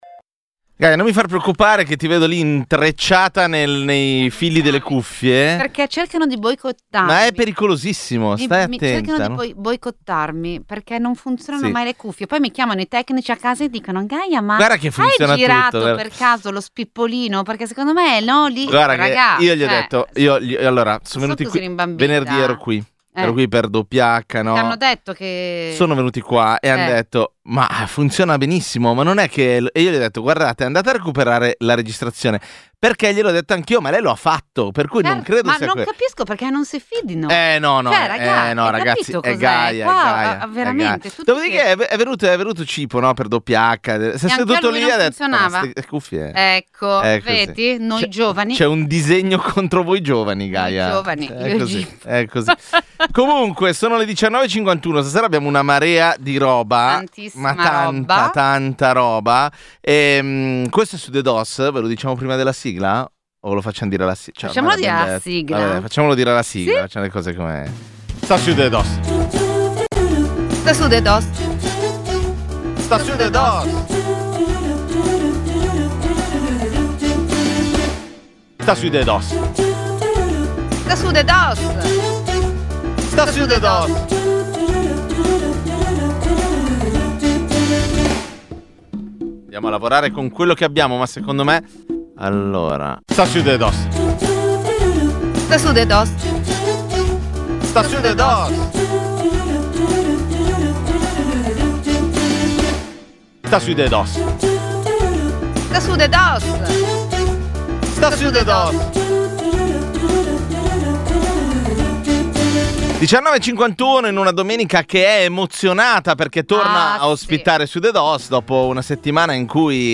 Sudedoss è il programma di infotainment che ogni domenica sera dalle 19.45 alle 21.30 accompagna le ascoltatrici e gli ascoltatori di Radio Popolare con leggerezza, ironia e uno sguardo semiserio sull’attualità.